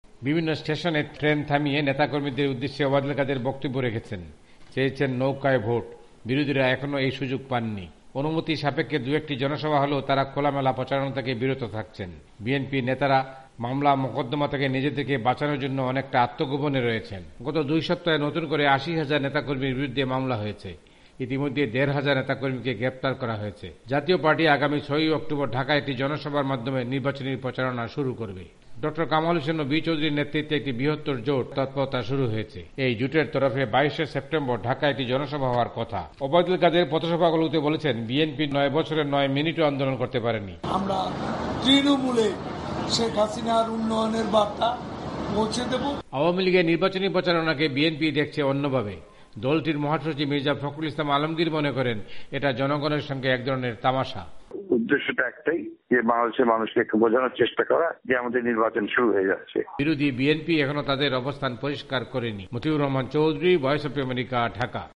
ঢাকা থেকে মতিউর রহমান চৌধুরীর রিপোর্ট